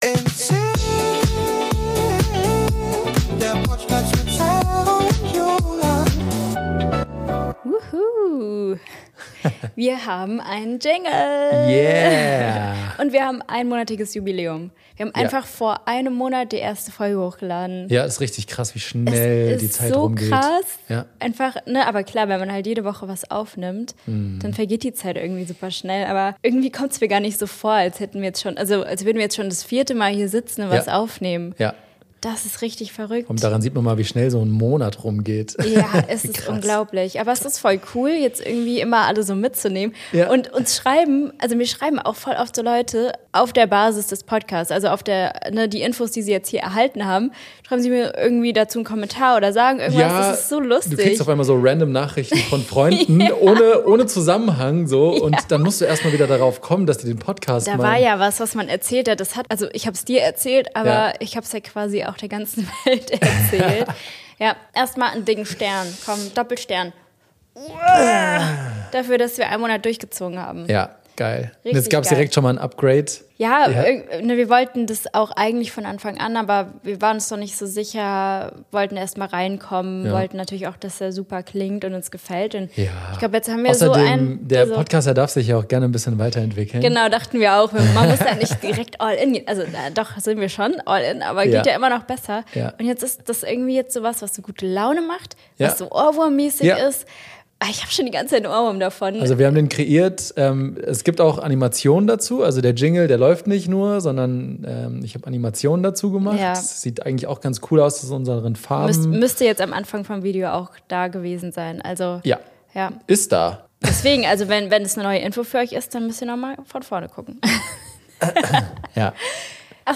Neuer Jingle, neue Folge - aber wie sind wir eigentlich DJs geworden?